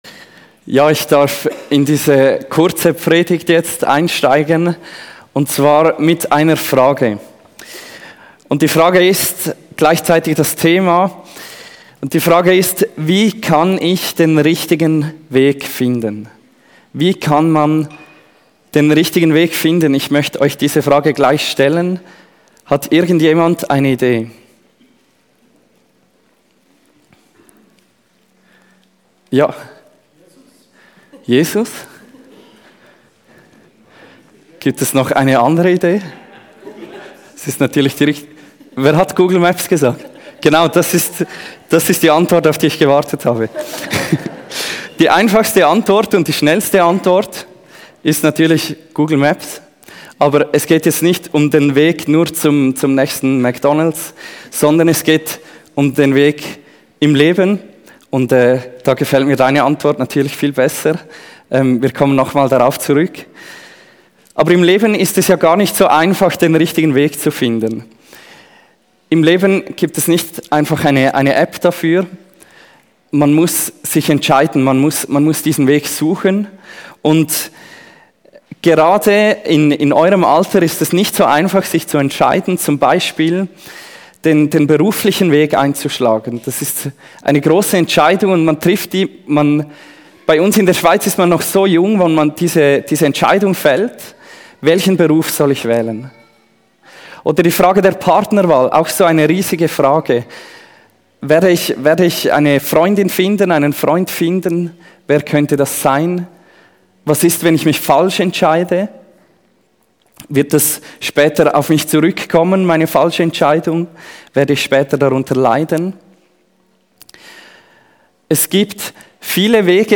Online Predigt